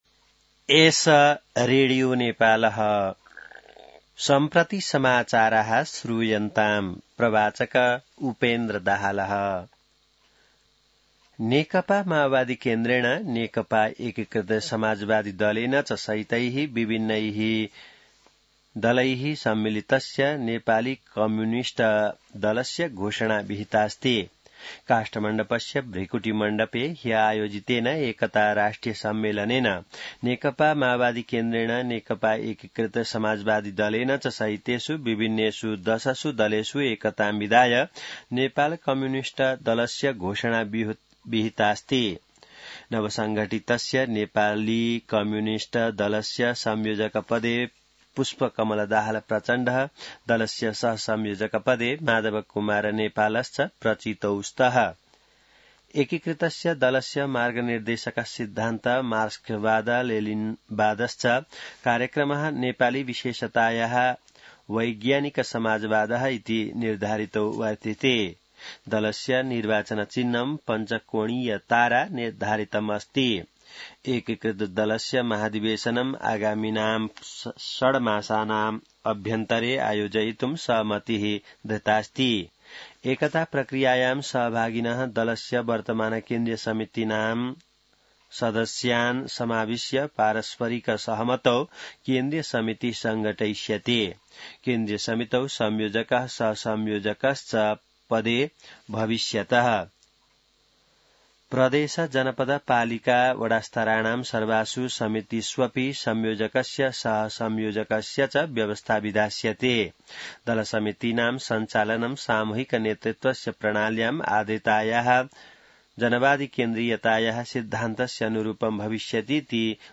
संस्कृत समाचार : २० कार्तिक , २०८२